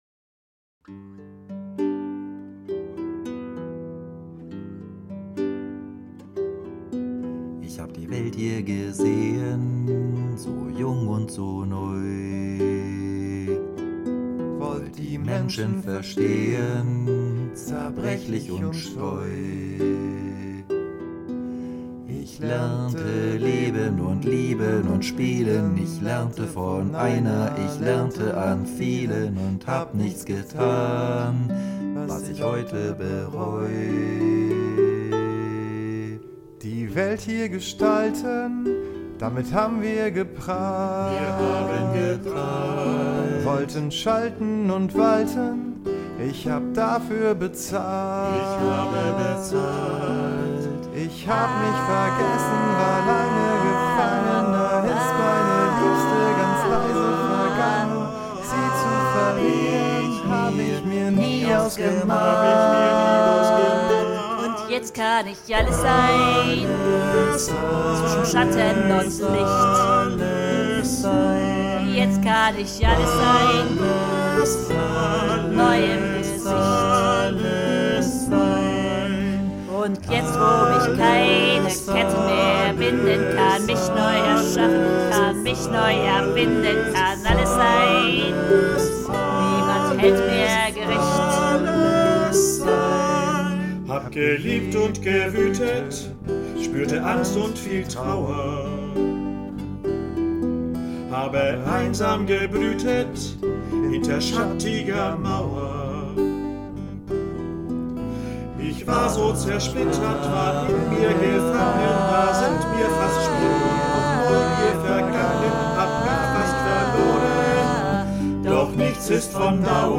Tenor
Schrammelgitarre